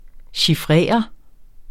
Udtale [ ɕiˈfʁεˀʌ ]